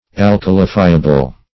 Search Result for " alkalifiable" : The Collaborative International Dictionary of English v.0.48: Alkalifiable \Al"ka*li*fi`a*ble\, a. [Cf. F. alcalifiable.] Capable of being alkalified, or converted into an alkali.
alkalifiable.mp3